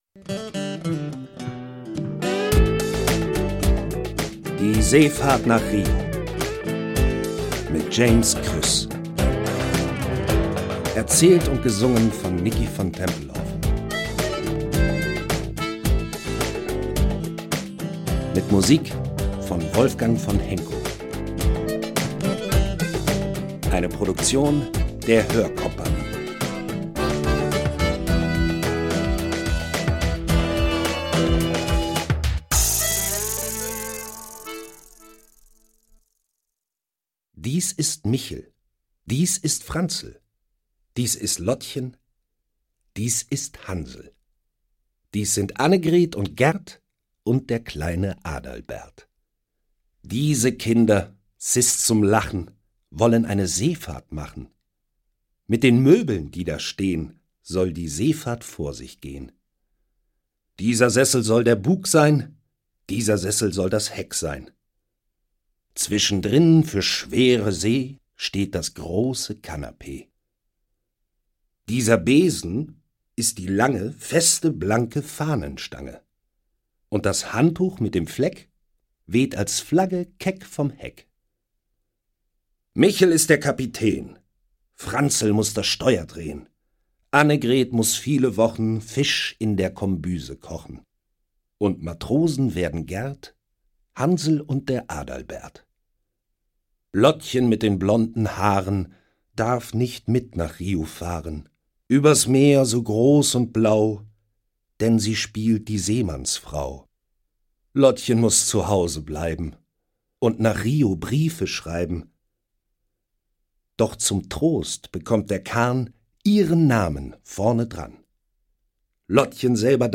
Lesung mit Musik